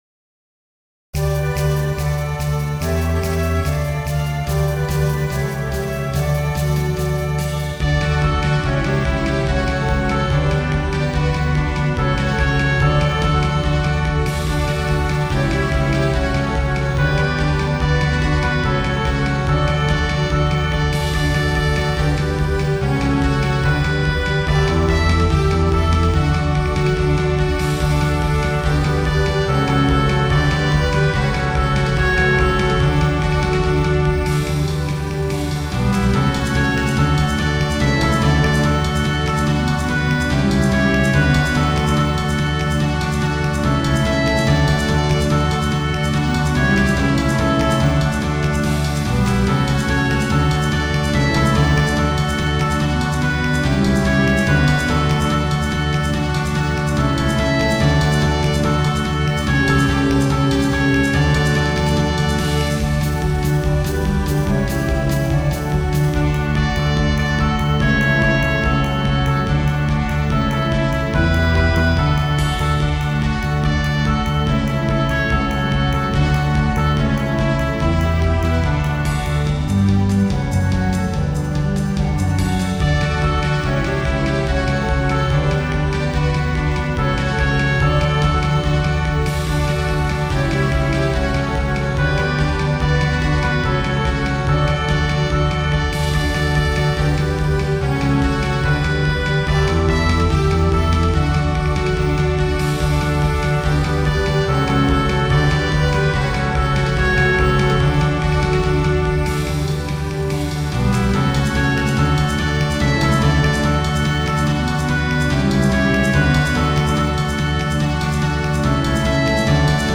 ニ長調